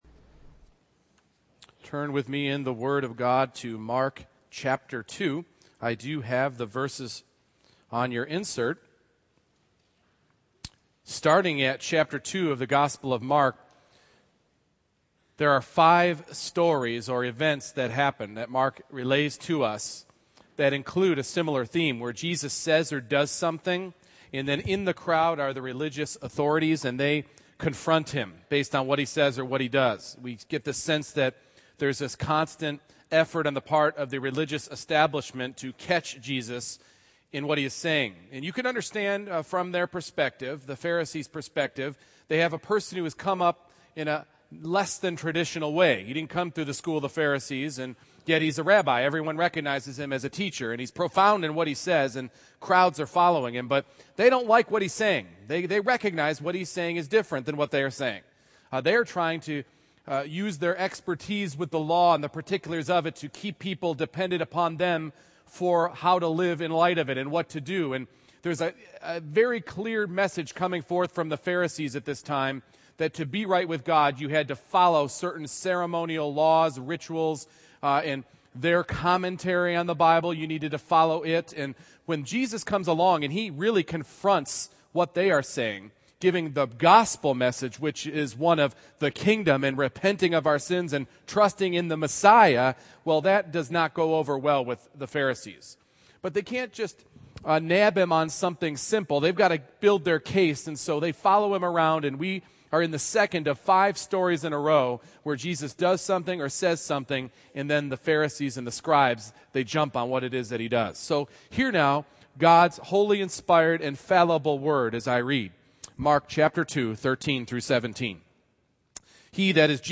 Mark 2:13-17 Service Type: Morning Worship I. Jesus enlists sinners to save other sinners II.